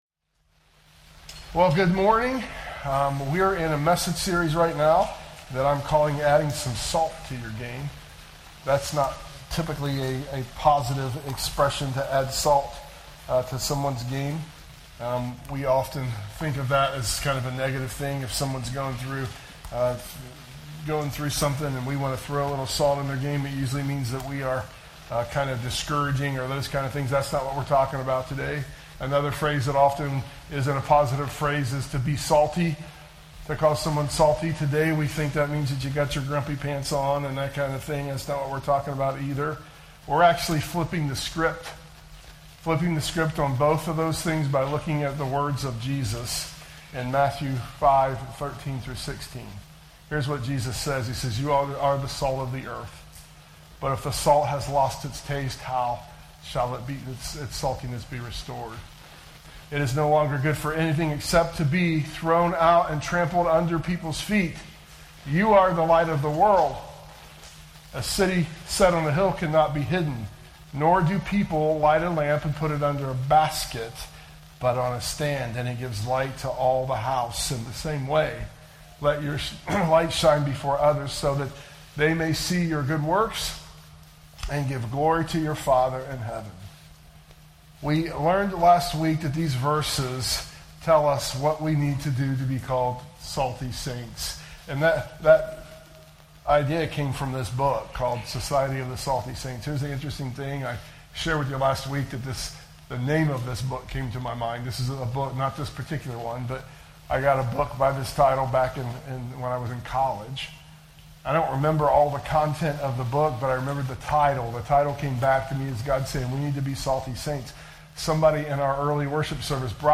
sermon_audio_mixdown_9_28_25.mp3